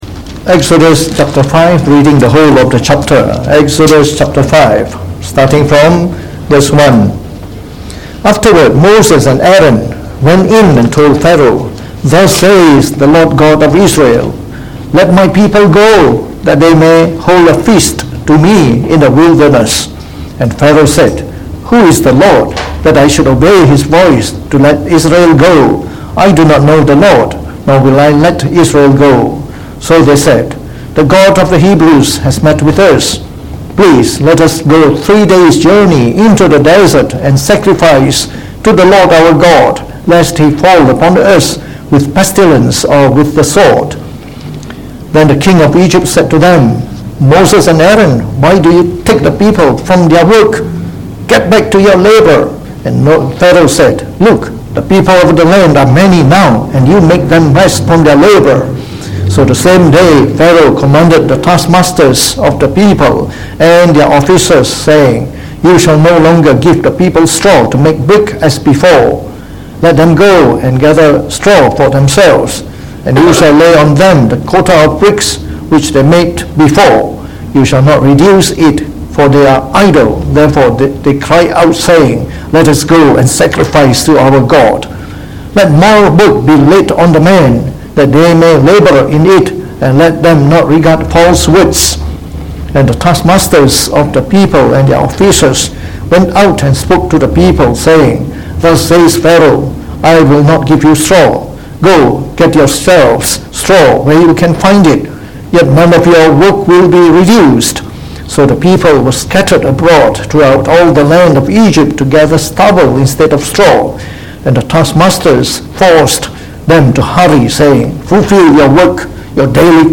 Preached on the 16th of June 2019.